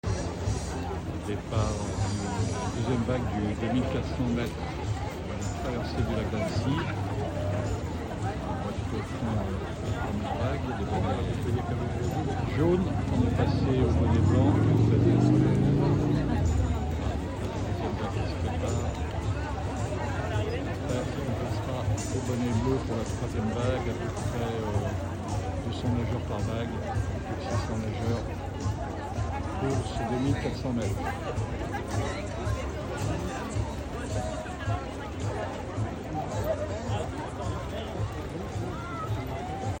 Départ du 2 400 mètres de la Traversée du lac d'Annecy organisée par les Dauphins d'annecy 🏊‍♀ 915 nageurs au départ 👍